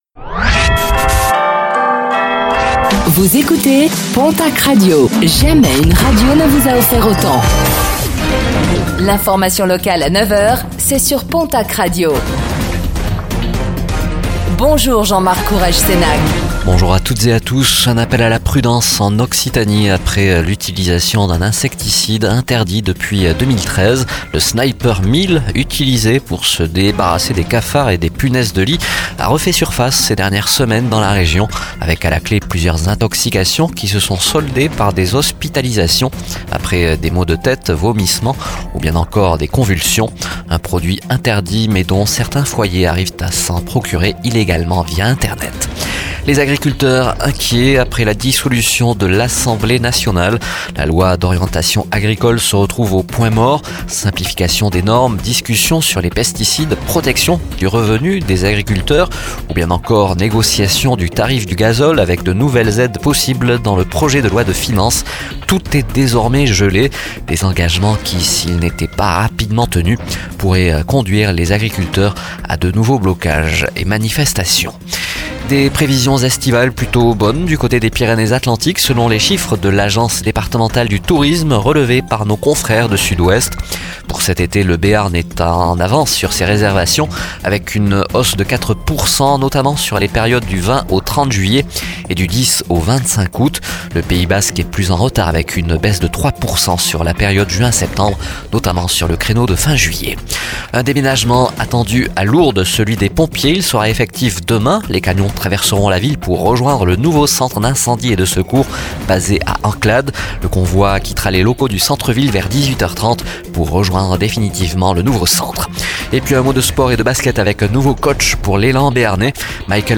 Réécoutez le flash d'information locale de ce jeudi 13 juin 2024